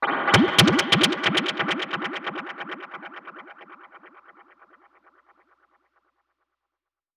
Index of /musicradar/dub-percussion-samples/134bpm
DPFX_PercHit_B_134-06.wav